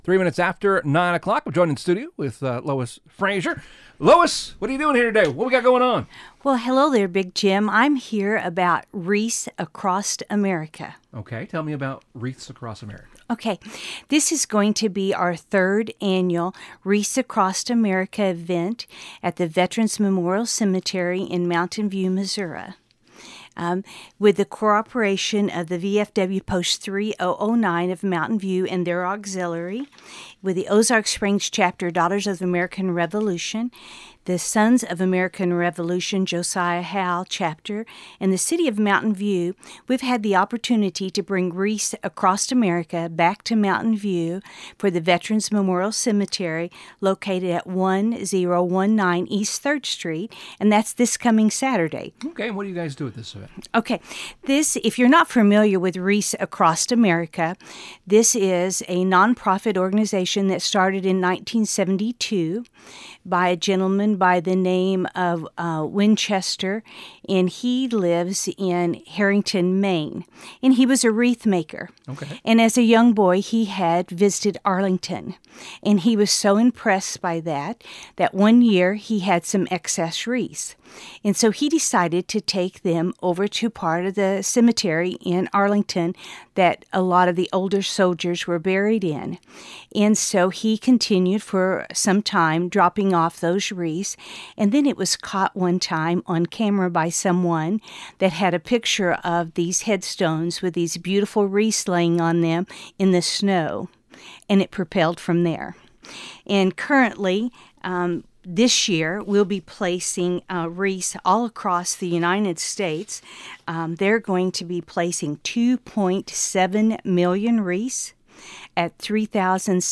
3rd Annual “Wreaths Across America” Taking Place in Mountain View (Audio Interview)